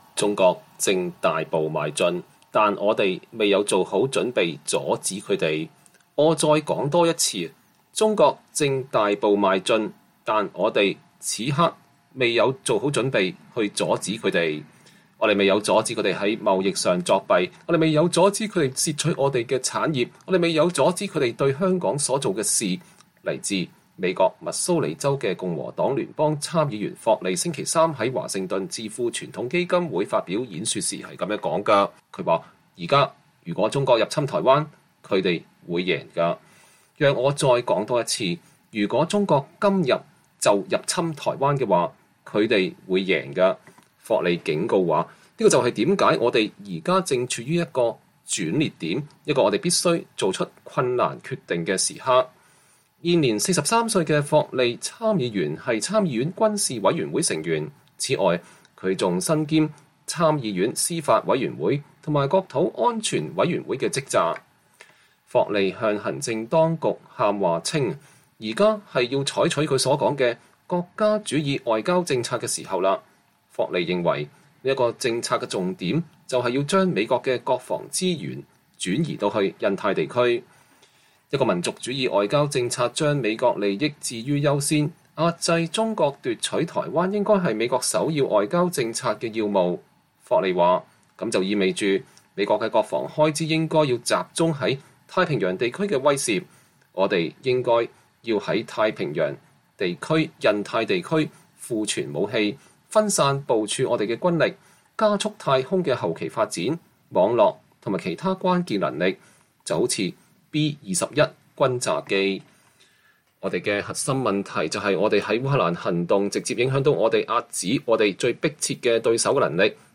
共和黨聯邦參議員喬什·霍利2023年2月16日在華盛頓智庫傳統基金會發表演講，呼籲美國將軍事資源重心移轉至印太地區。